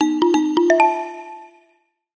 木琴（マリンバ）の音がSNSの通知をお知らせします。